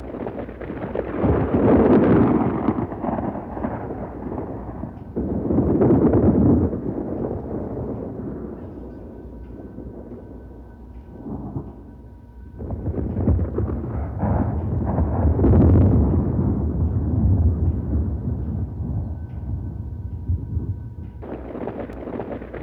Index of /90_sSampleCDs/E-MU Producer Series Vol. 3 – Hollywood Sound Effects/Ambient Sounds/Rolling Thunder